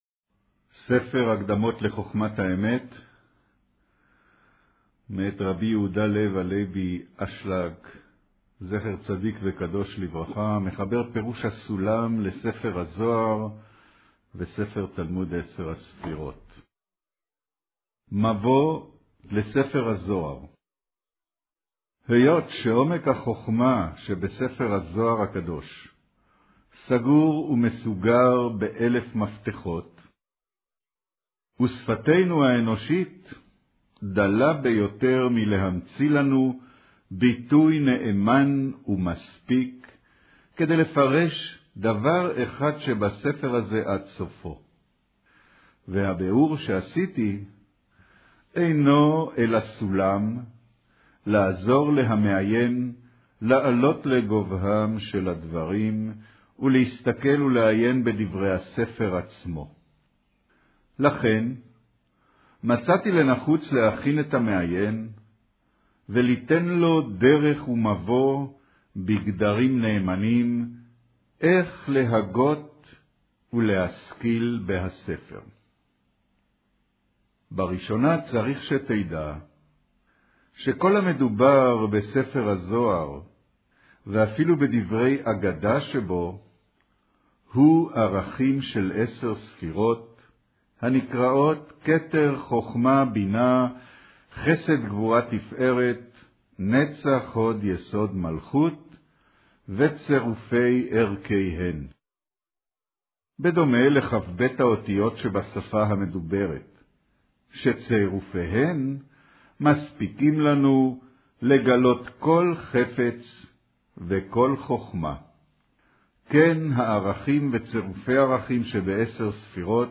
קריינות מבוא לספר הזהר אות א' - כב'